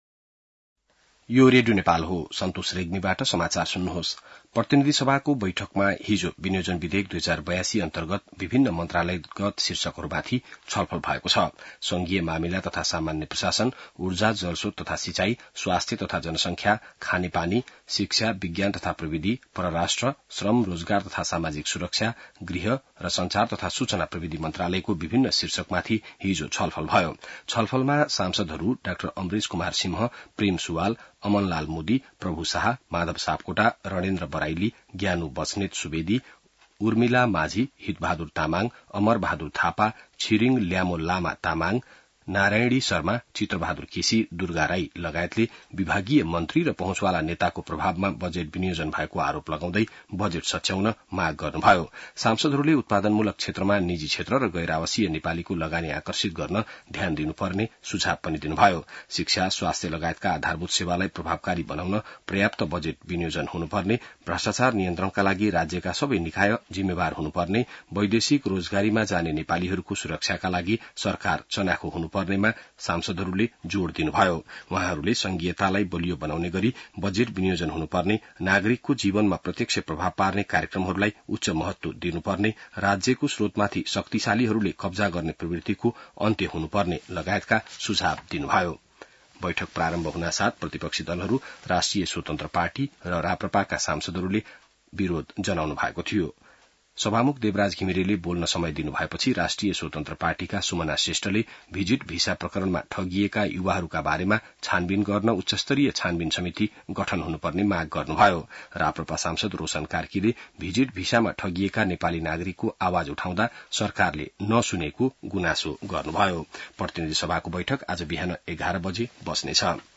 बिहान ६ बजेको नेपाली समाचार : ८ असार , २०८२